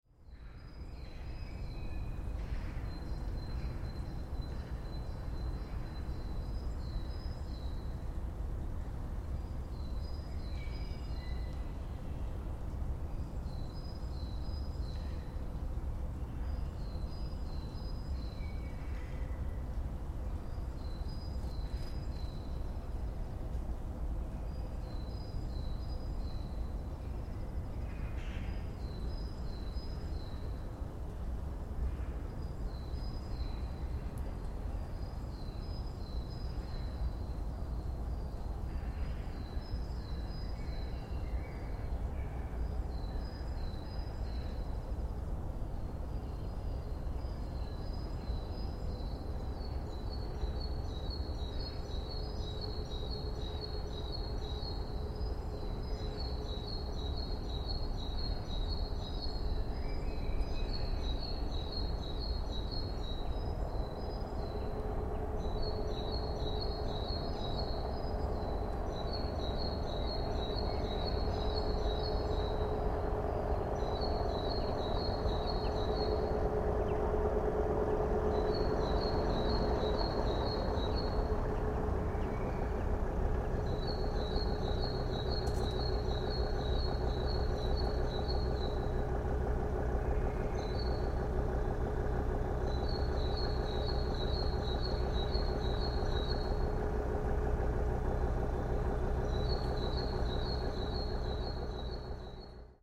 Звуки утра
Звуки города в ранний майский час: 4-5 утра